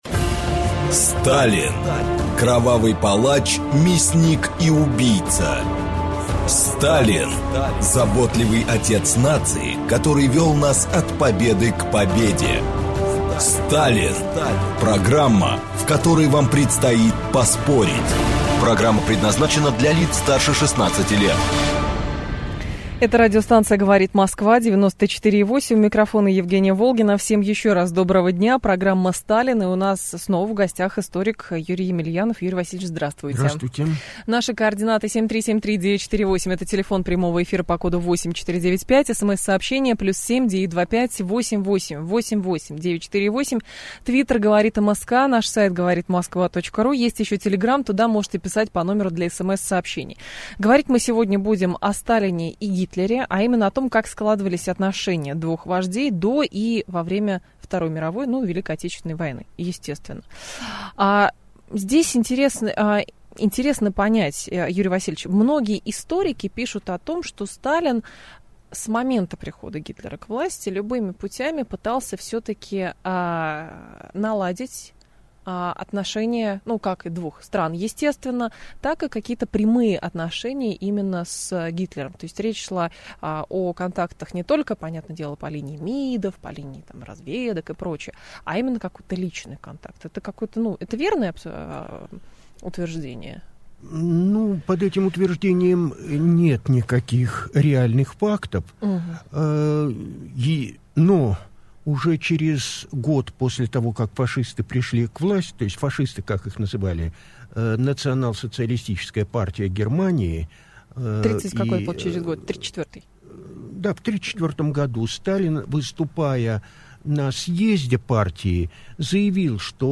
Аудиокнига Сталин и Гитлер | Библиотека аудиокниг
Прослушать и бесплатно скачать фрагмент аудиокниги